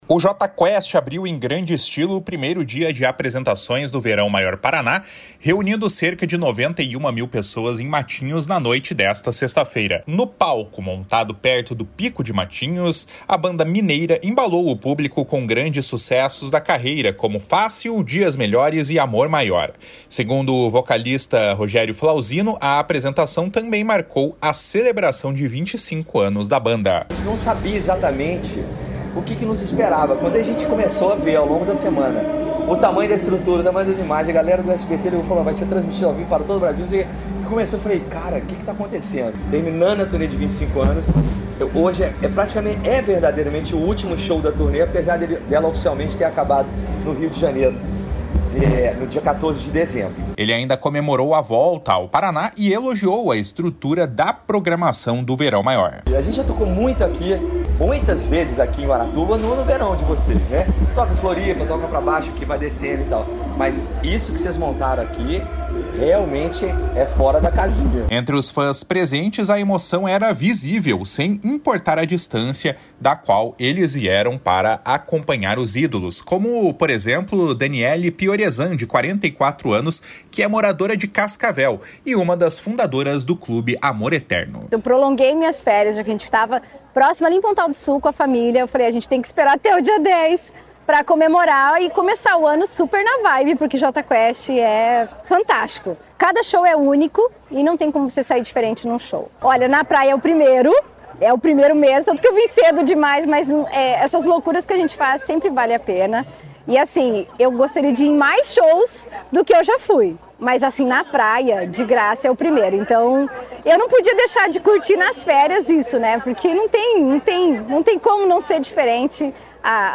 // SONORA ROGÉRIO FLAUSINO //